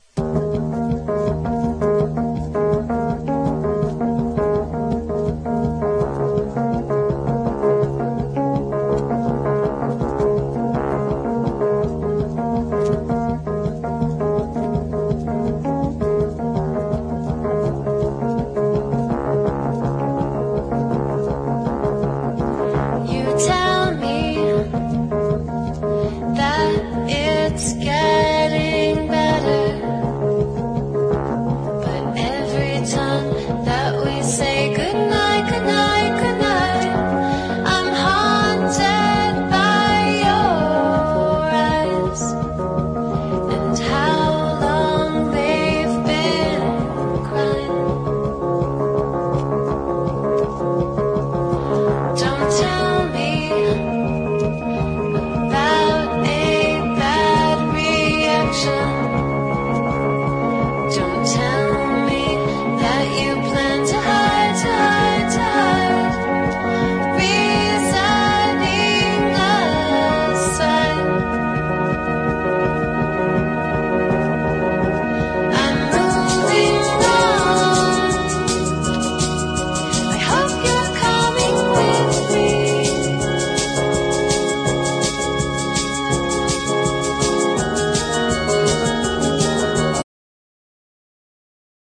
INDIE DANCE